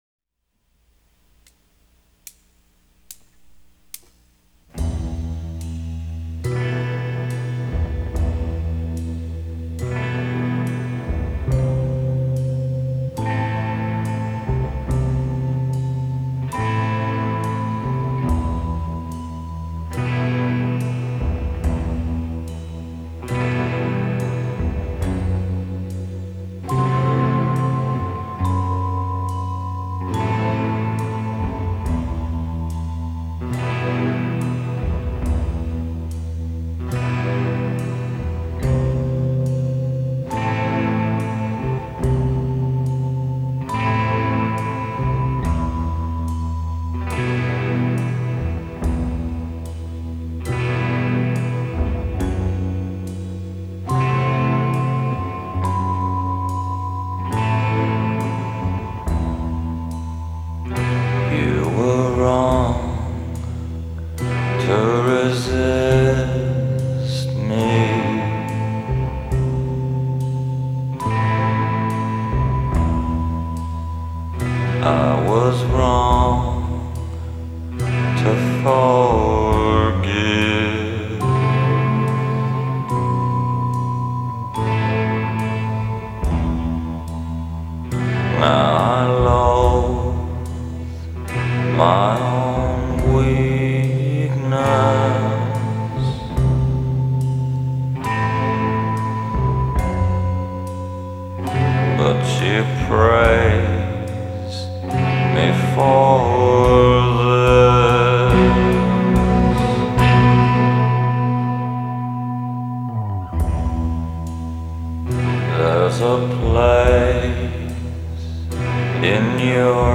Post-rock
Experimental Rock